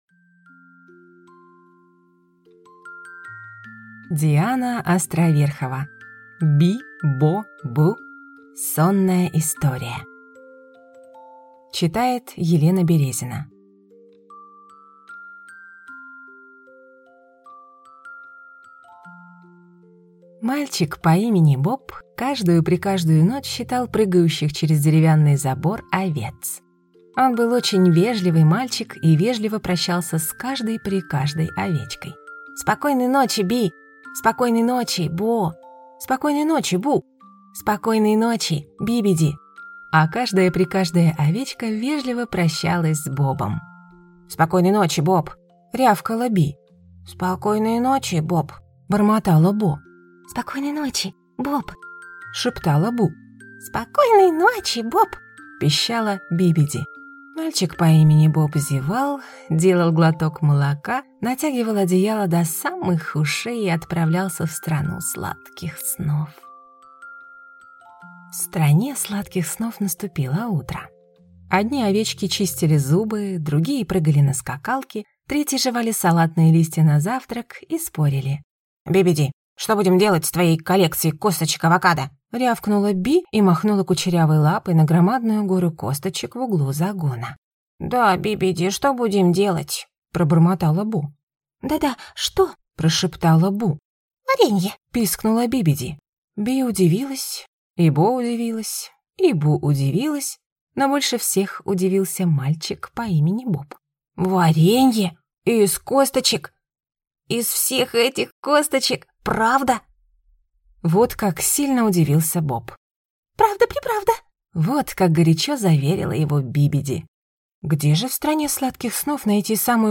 Aудиокнига Би! Бо! Бу! Сонная история